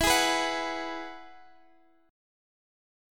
Eadd9 chord